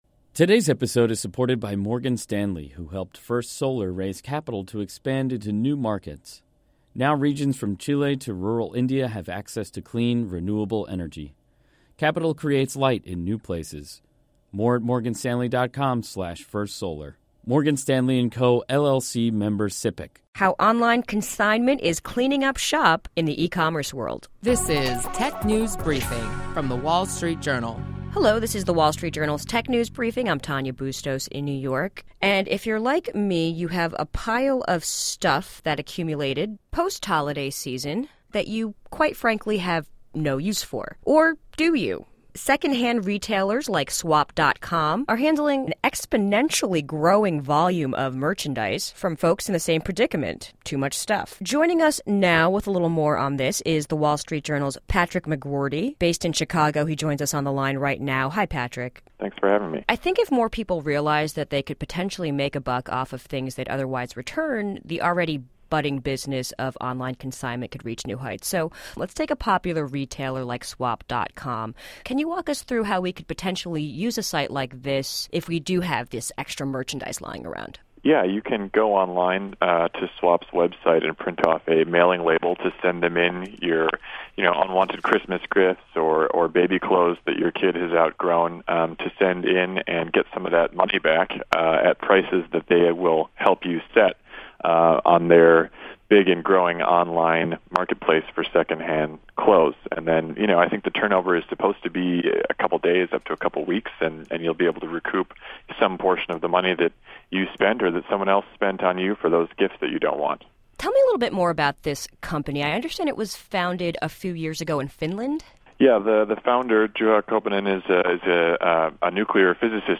Stay informed on the latest trends with daily insights on what’s hot and happening in the world of technology. Listen to our reporters discuss notable company news, new tech gadgets, personal technology updates, app features, start-up highlights and more.